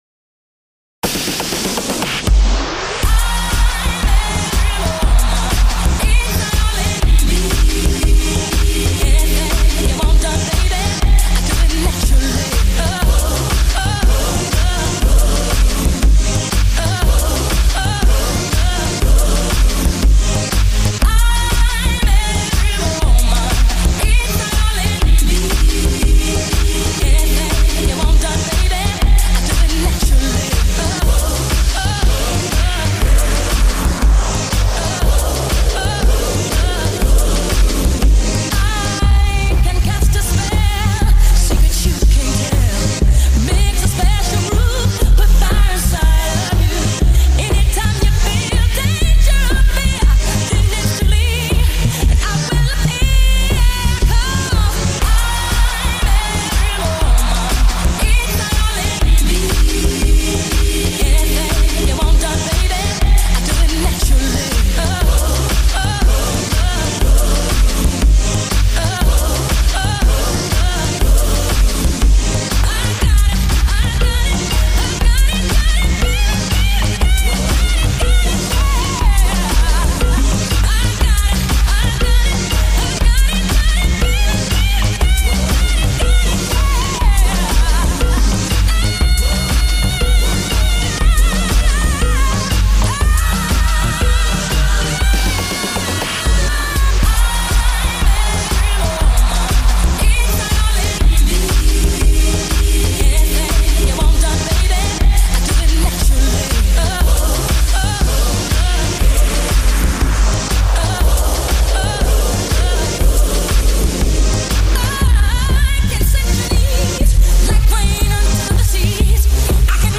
Electro-Swing style.